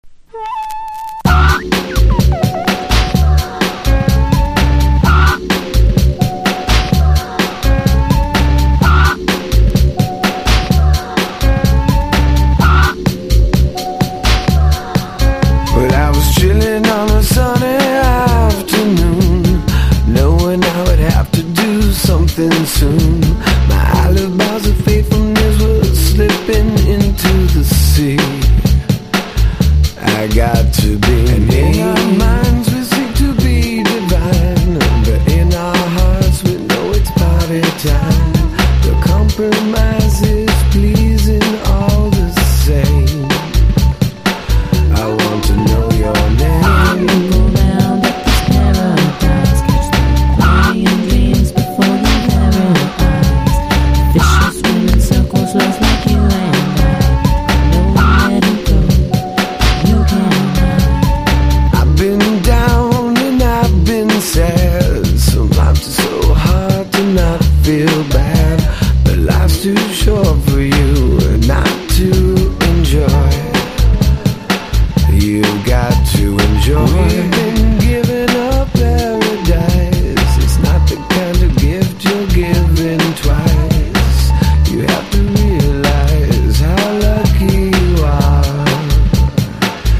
JAZZY HIPHOP# BREAK BEATS / BIG BEAT
キャッチーなサンプリングにヴォーカルの具合がBECK的な感じもする1枚！